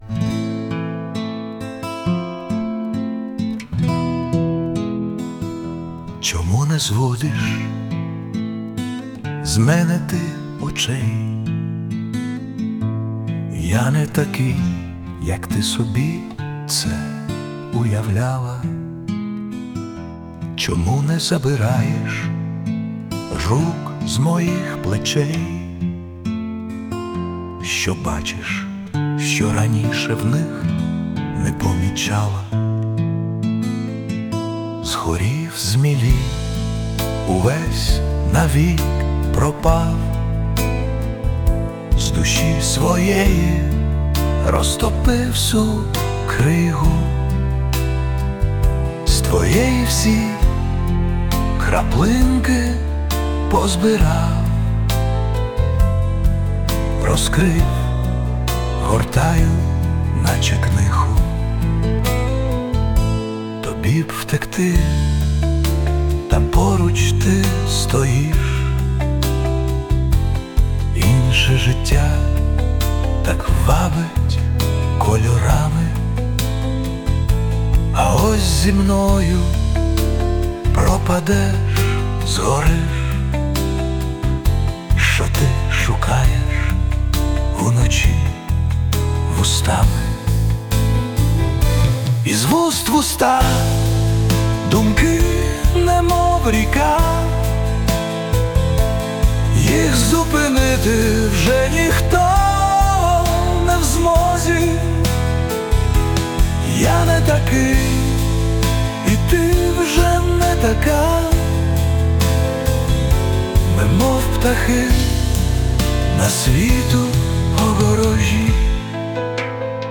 Присутня допомога SUNO
СТИЛЬОВІ ЖАНРИ: Ліричний
але так ... так ... ніжна
Я зрозуміла, шо звук оброблений, але голос вже більш людський, не штучно створений наче